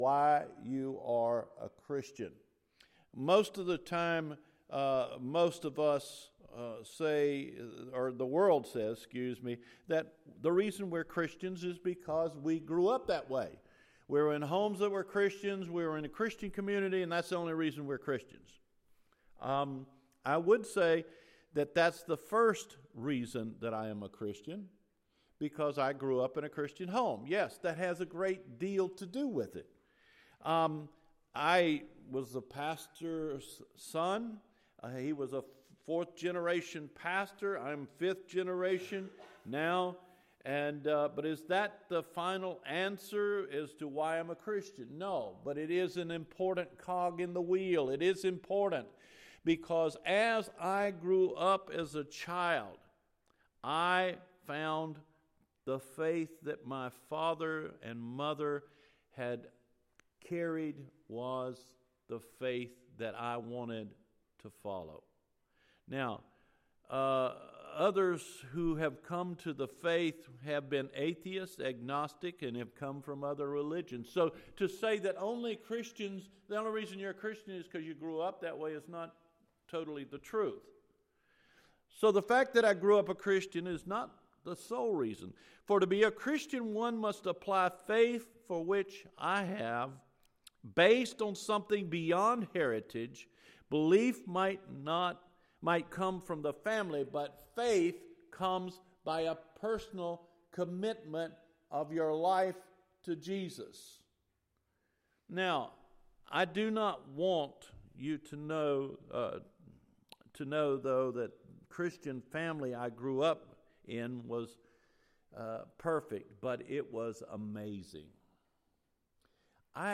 Why Are We Christians? April 22 Sermon